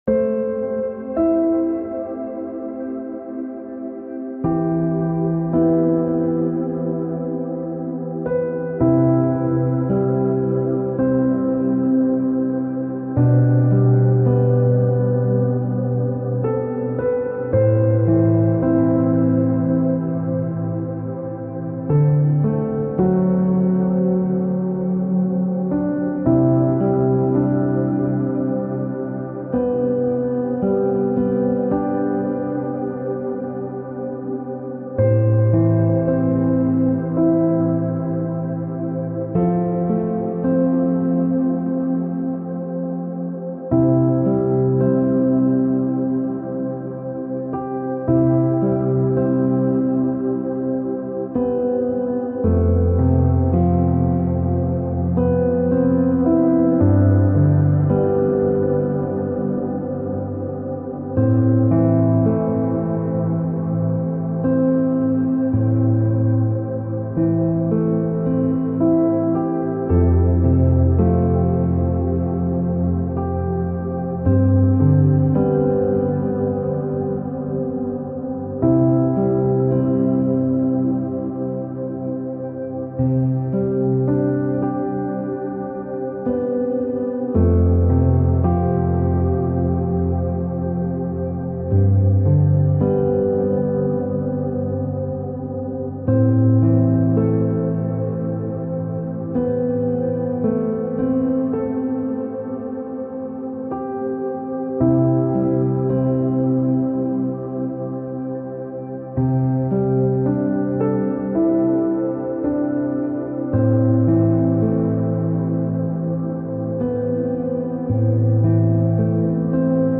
深い眠りの回復のための嵐の夜の雨